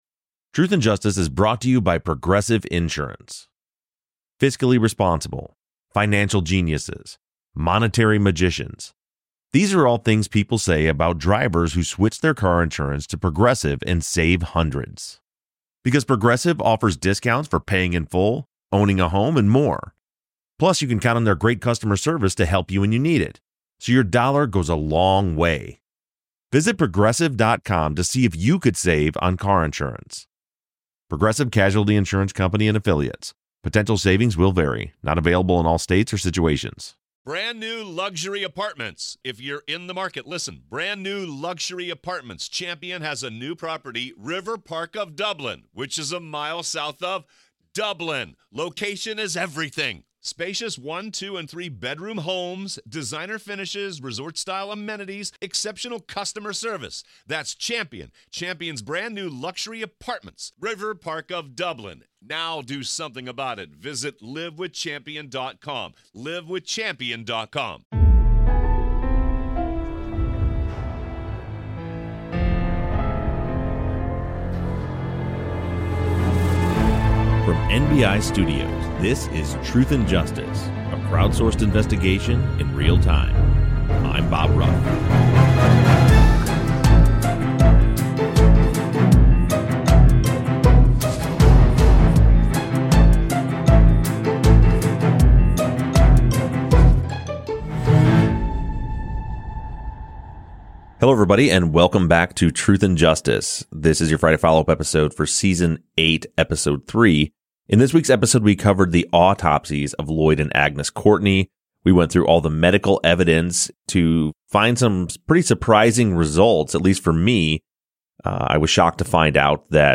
True Crime, Documentary, Society & Culture
The guys record another remote episode where they discuss listener questions over the latest developments in the Season 8 case.